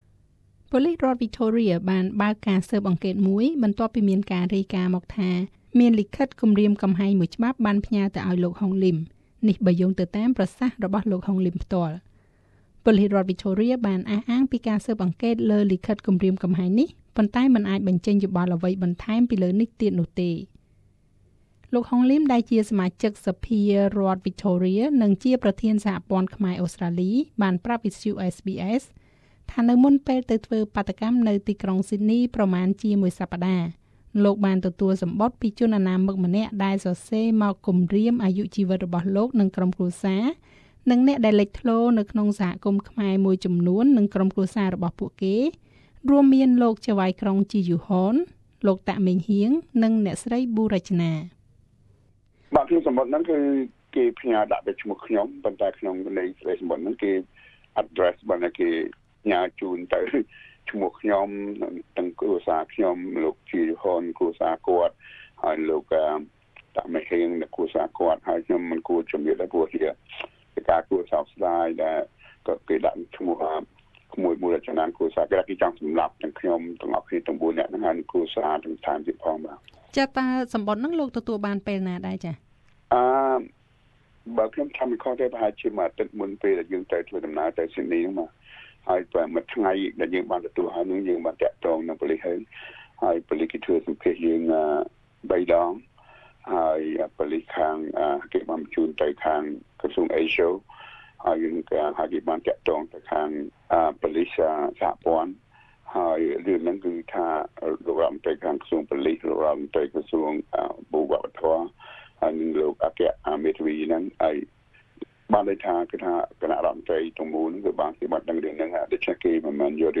សូមស្តាប់បទសម្ភាសន៍ជាមួយនឹងលោក ហុង លីម ដូចតទៅ។